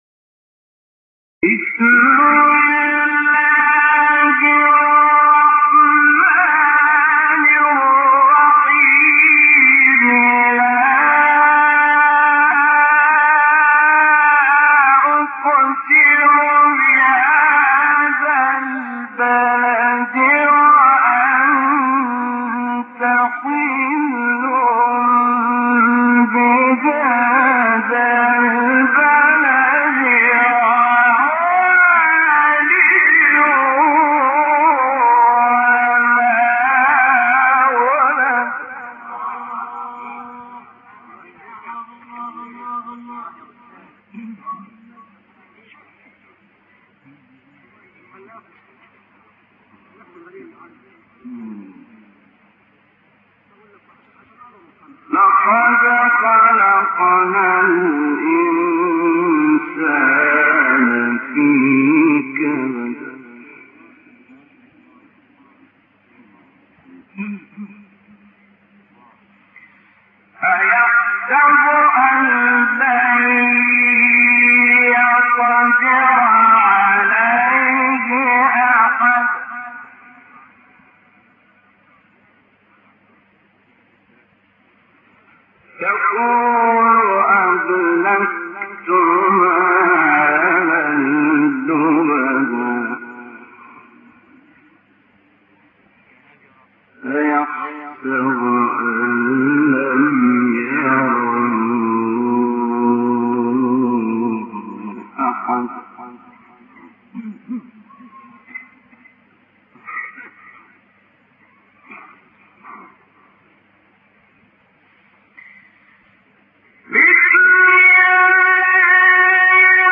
آیه 1-12 سوره بلد استاد شحات مقام سه گاه | نغمات قرآن | دانلود تلاوت قرآن